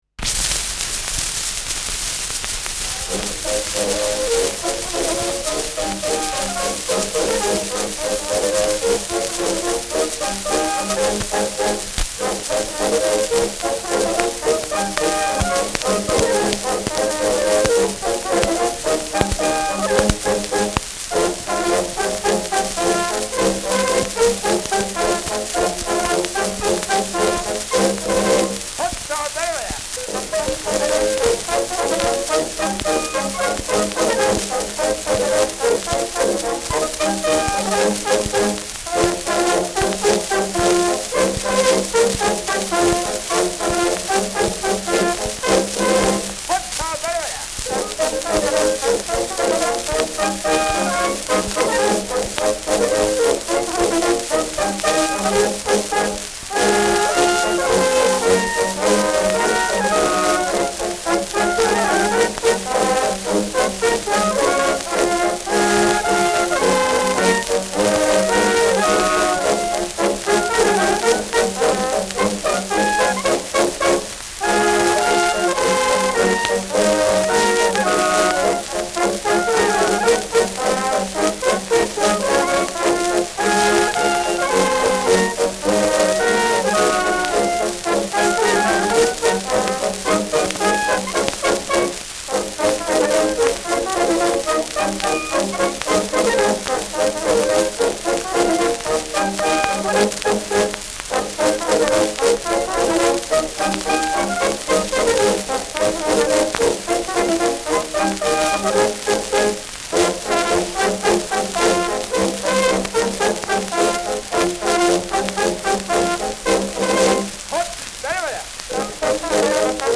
Schellackplatte
starkes Rauschen : gelegentliches Knacken : präsentes Knistern : abgespielt : gelegentliches „Schnarren“
Zwischenruf „Hopsa, Bärbele“.